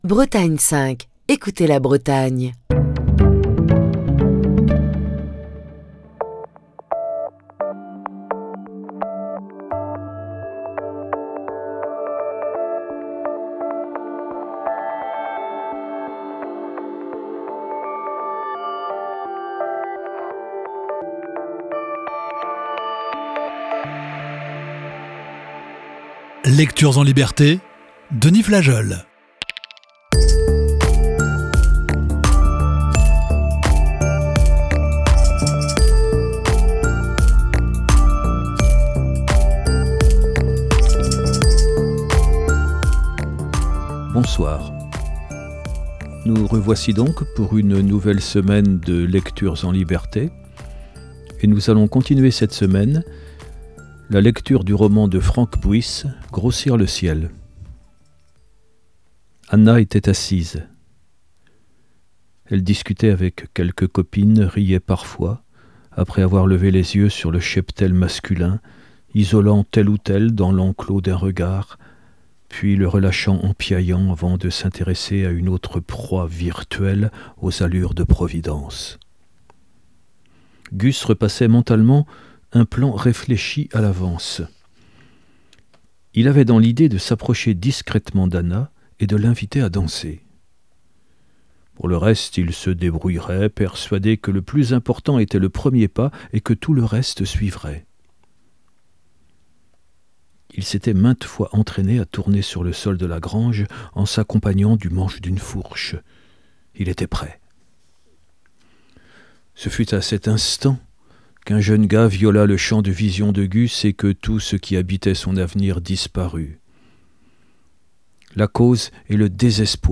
Émission du 9 octobre 2023.